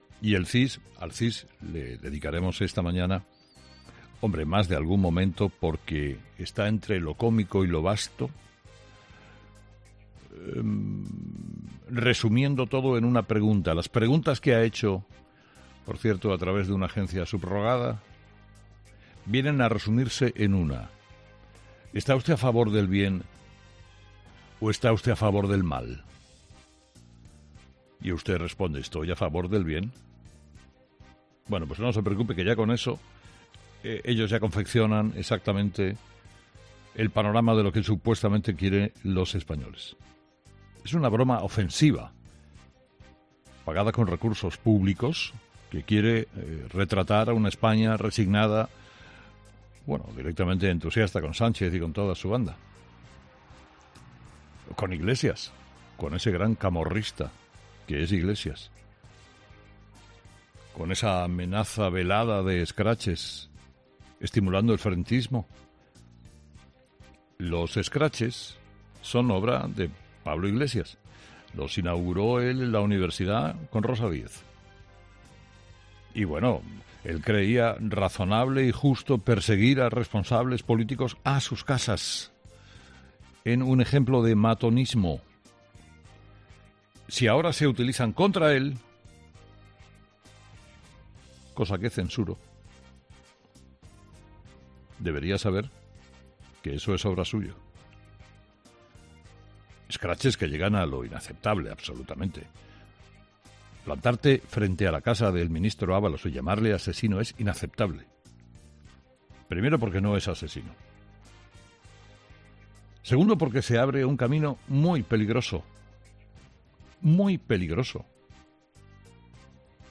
El director de 'Herrera en COPE', Carlos Herrera, ha lanzado este mensaje a Pedro Sánchez y a Tezanos por los resultados del CIS en el mes de mayo